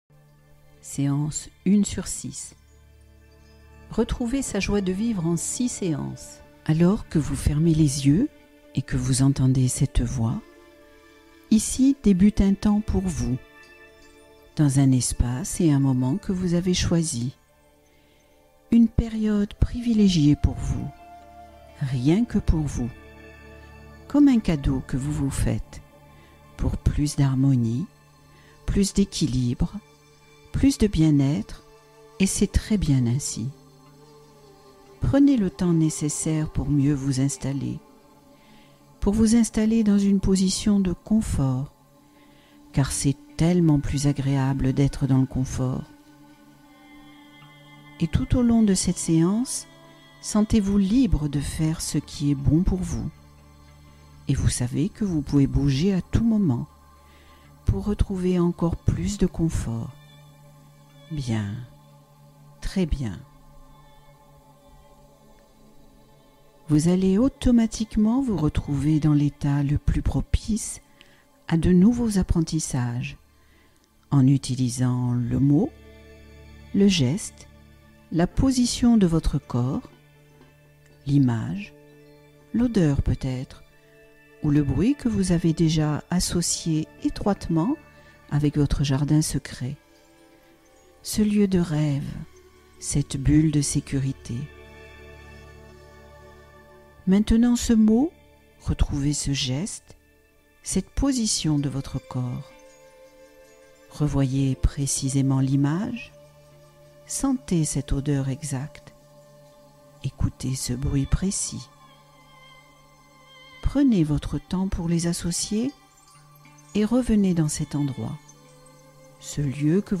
Retrouver la joie de vivre : hypnose en 6 étapes